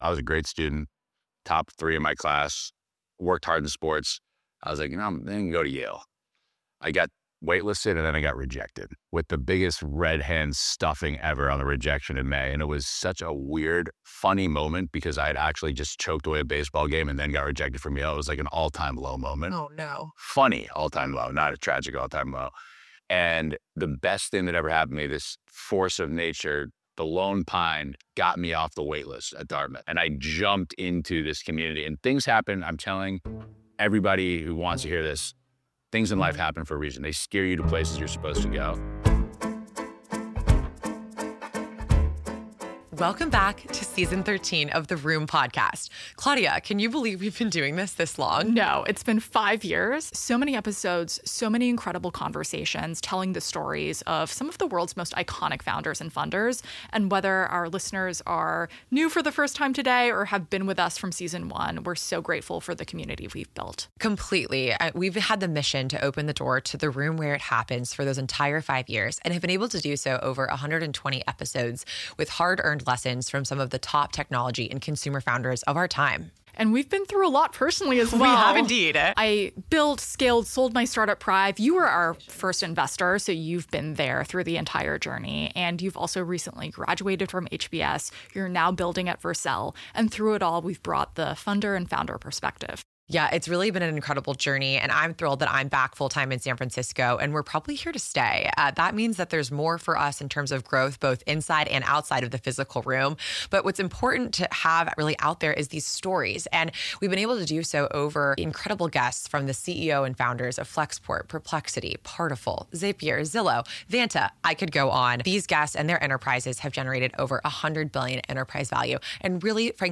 We are back with another live rendition of The Room Podcast!